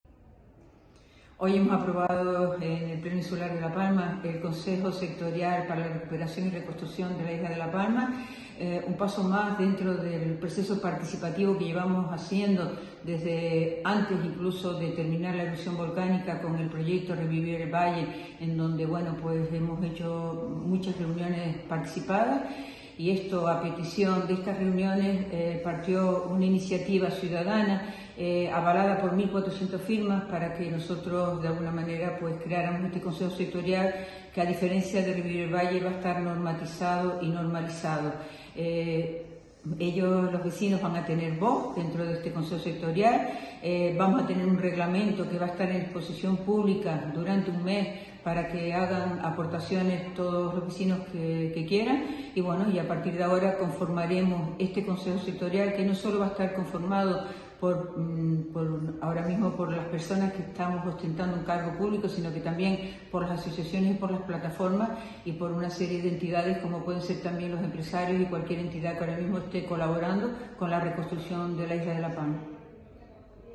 Declaraciones audio Nieves Rosa Arroyo Consejo Sectorial Reconstrucción.mp3